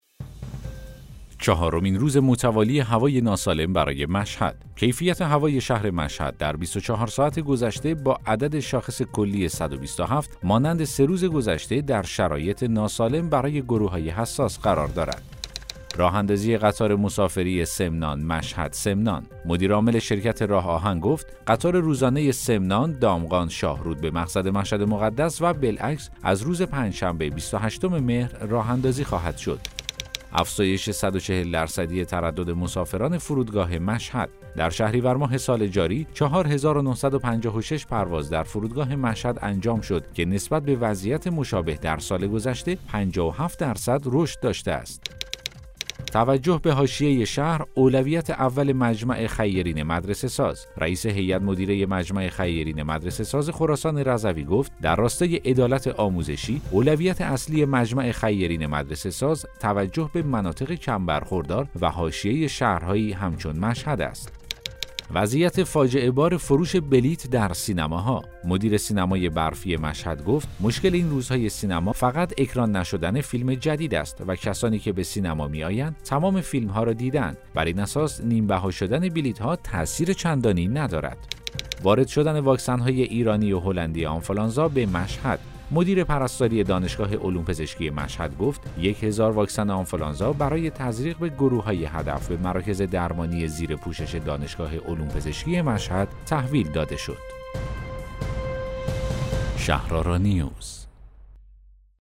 اخبار صوتی - دوشنبه صبح ۲۵ مهر ۱۴۰۱